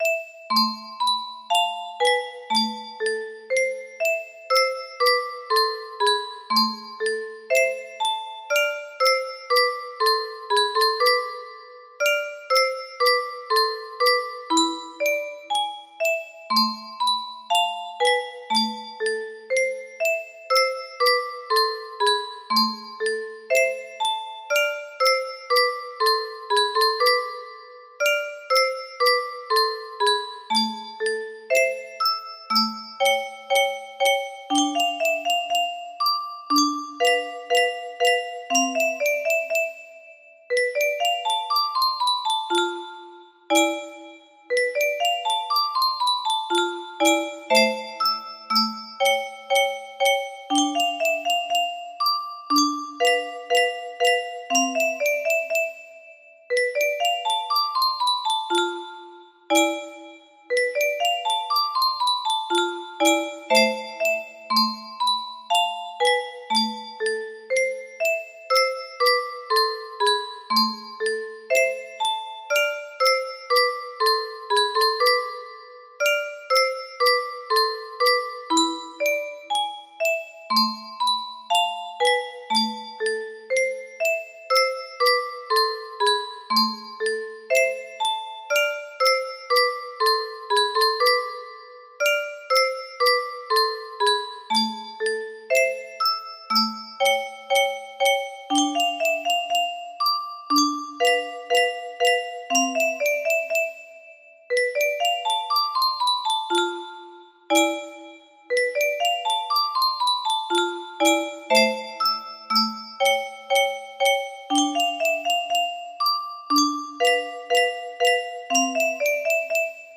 Канцона (Ф.да Милано) music box melody